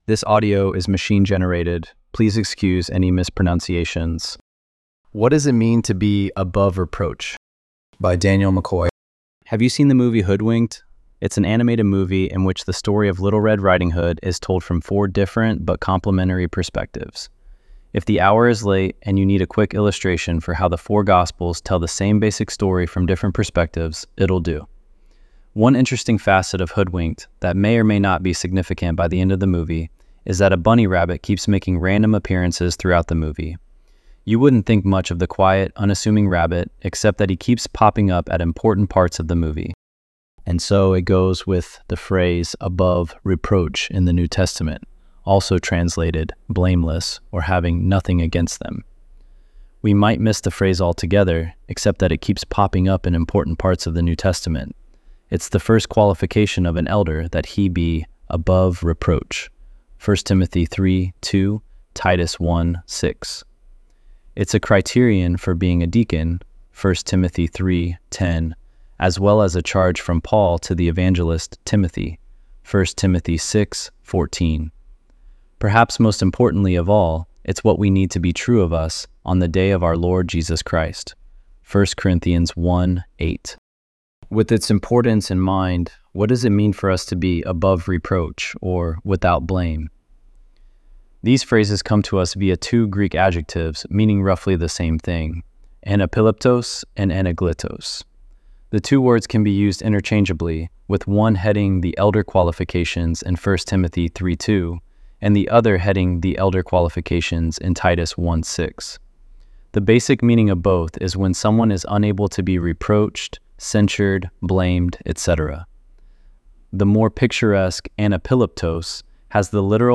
ElevenLabs_3_17-1.mp3